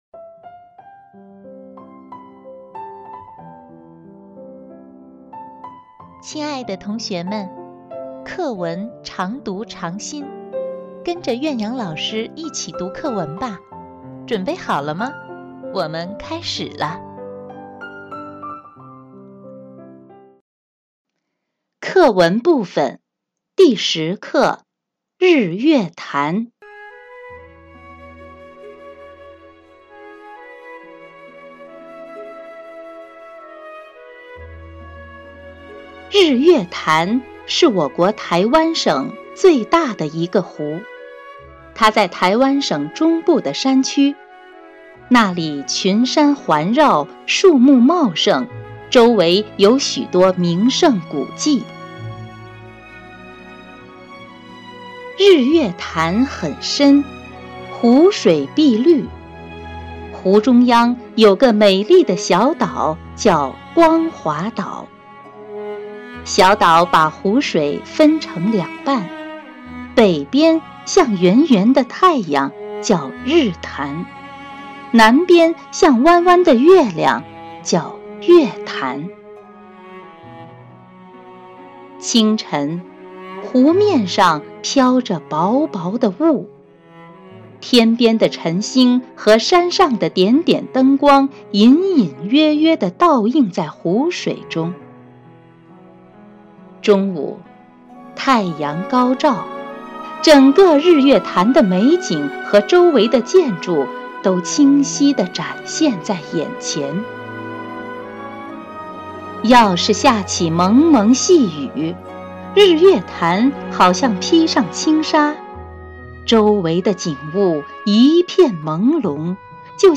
课文朗读